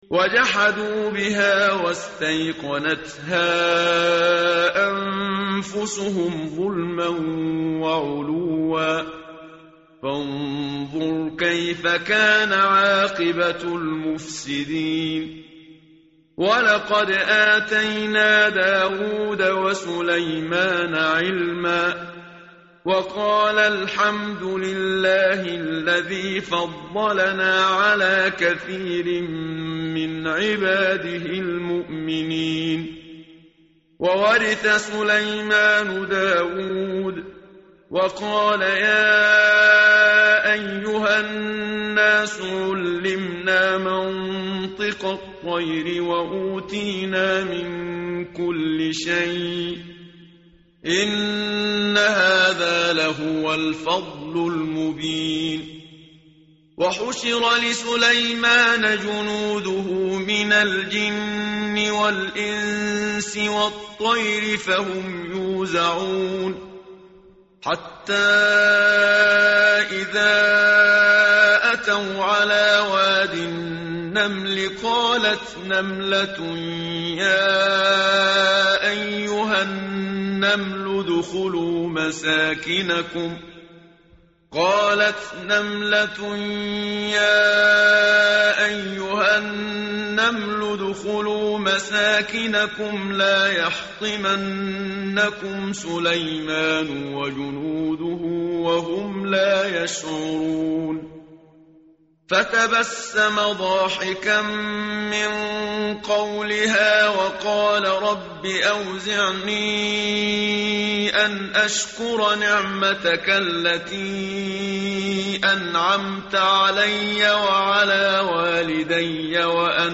tartil_menshavi_page_378.mp3